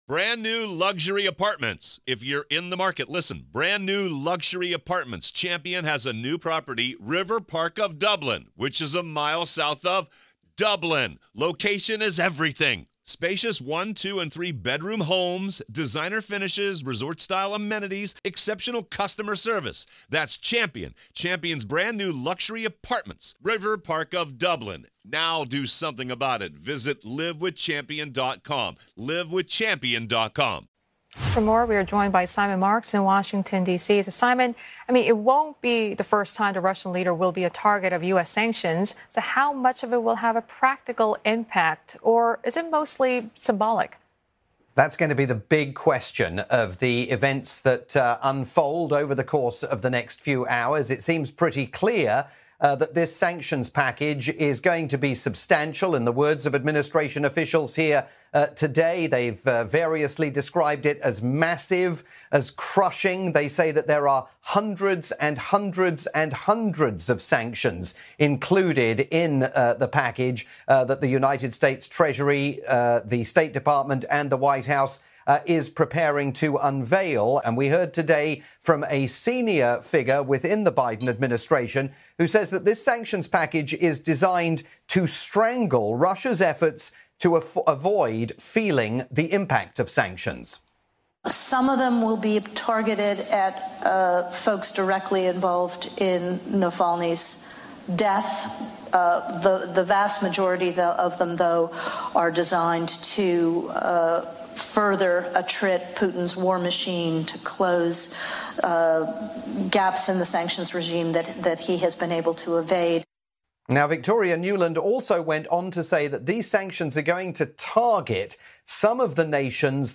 live update for pan-Asian news network CNA